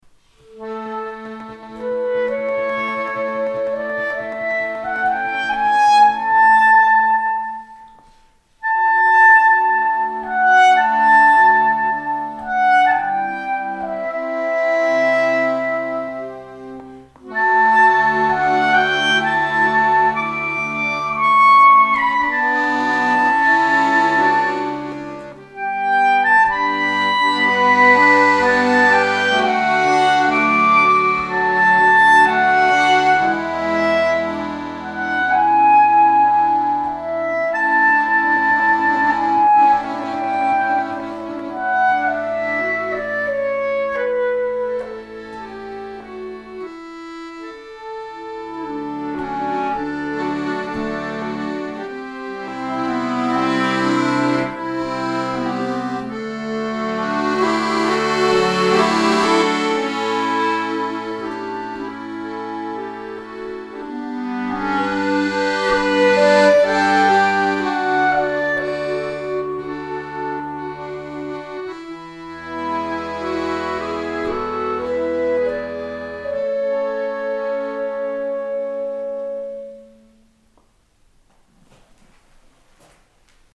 Je hoort 2x hetzelfde liedje, maar klinkt het ook hetzelfde?